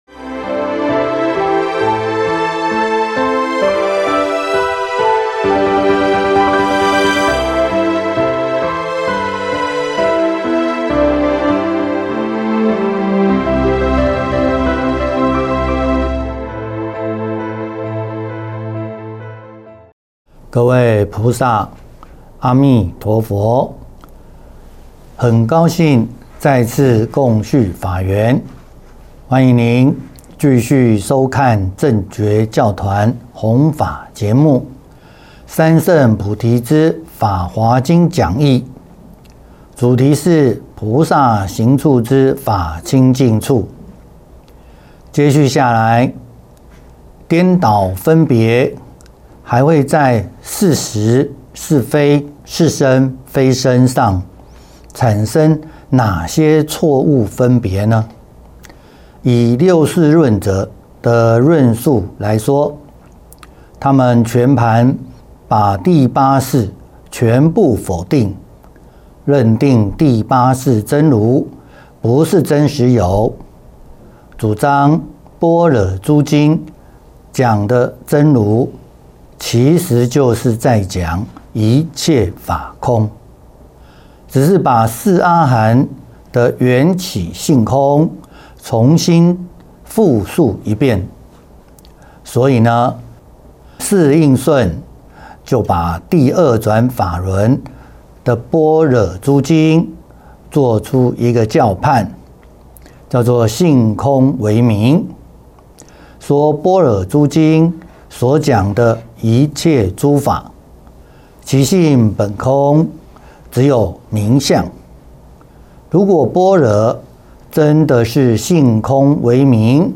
三乘菩提系列讲座，正觉同修会影音，同修会音频，同修会视频